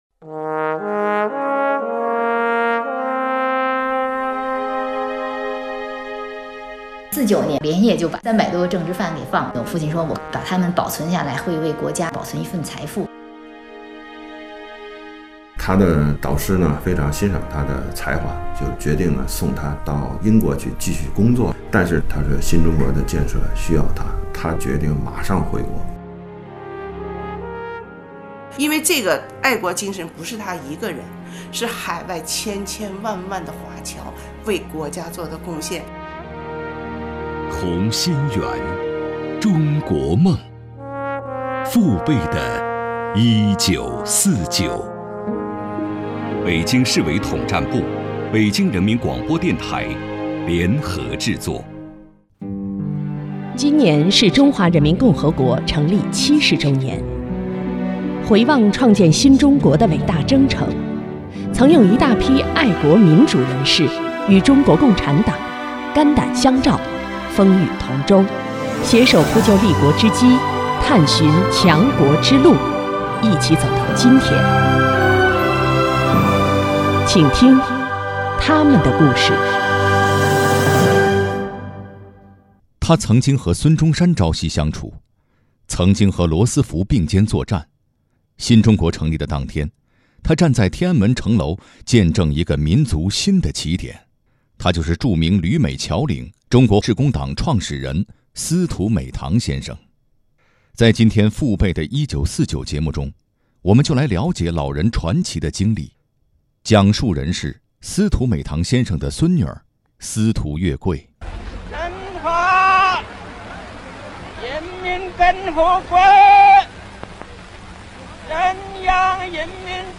2019年8月7日，由中共北京市委统战部与北京人民广播电台联合制作的《同心圆·中国梦——父辈的1949》口述史特别节目在北京人民广播电台举行开播仪式。